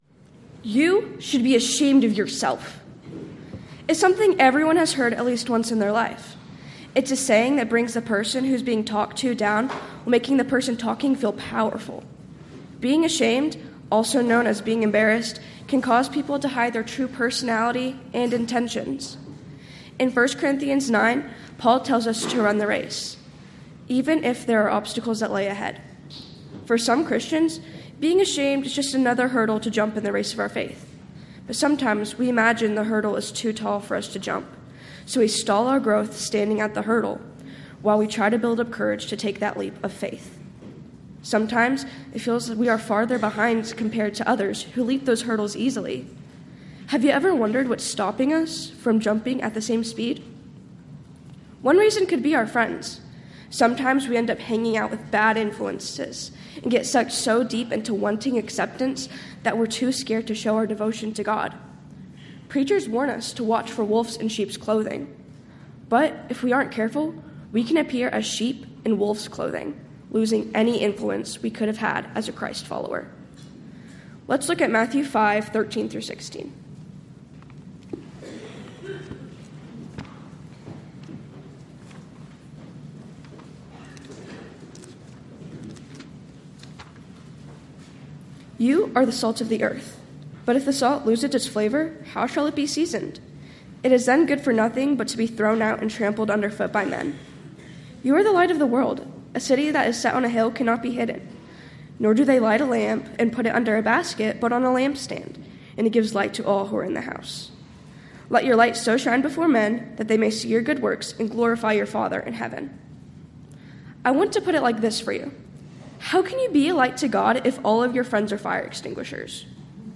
Title: Friday AM Devotional
Event: 13th Annual Texas Ladies in Christ Retreat